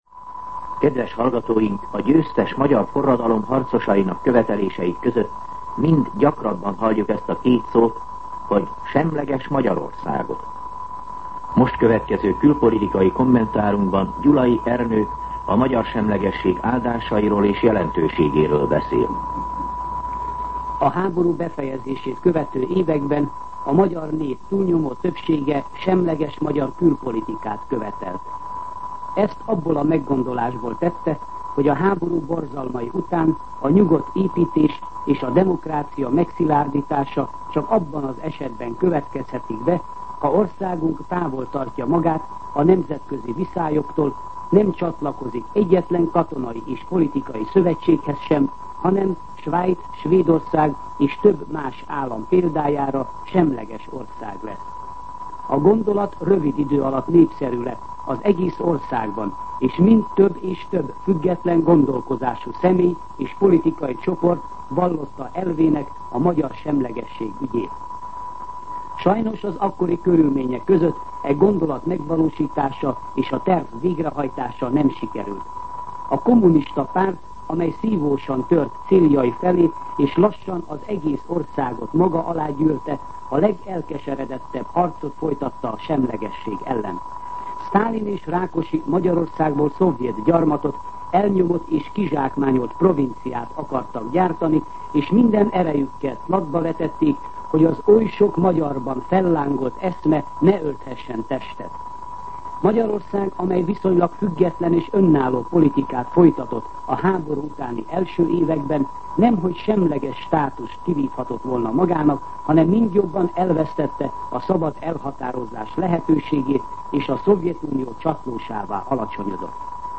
Külpolitikai kommentár